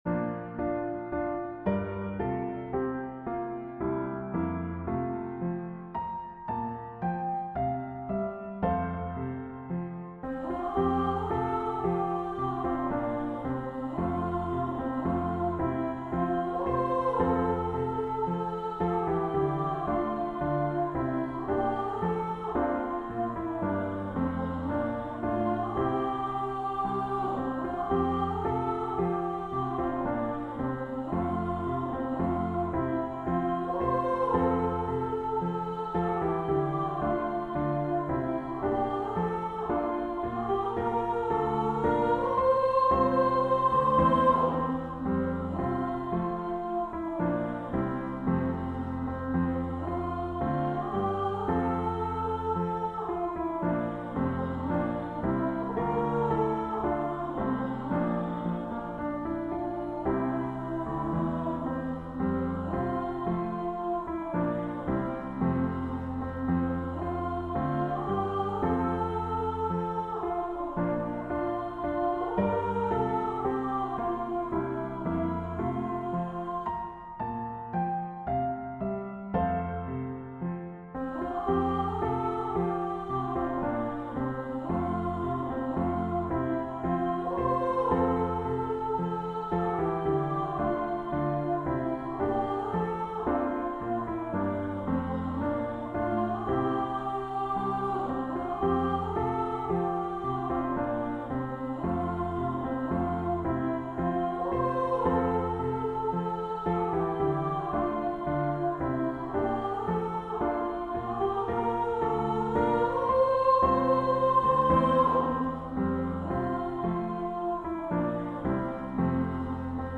Voicing/Instrumentation: Primary Children/Primary Solo , Vocal Solo